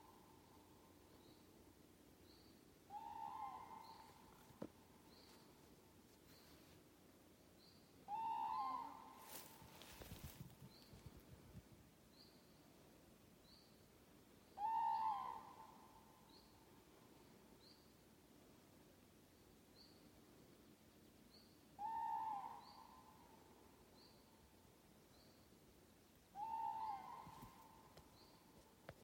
Common Crane, Grus grus
StatusSpecies observed in breeding season in possible nesting habitat